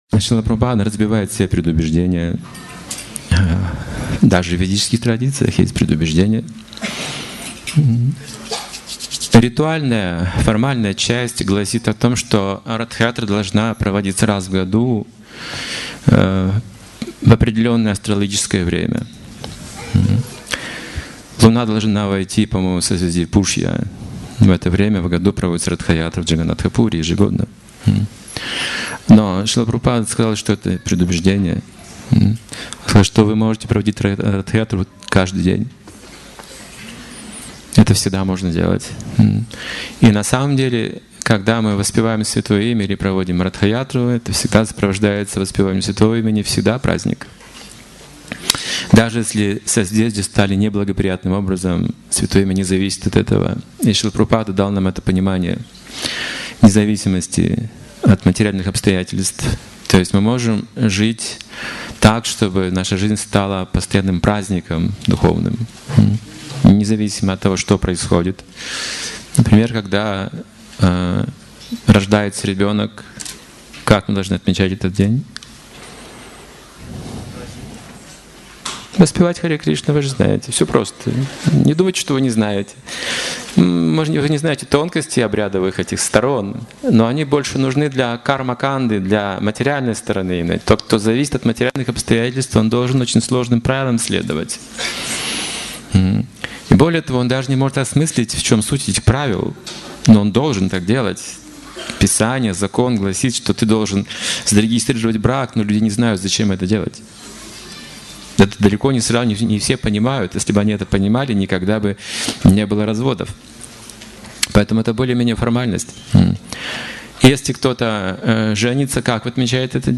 Лекция о Господе Джаганнатхе и духовной жизни в вайшнавской традиции.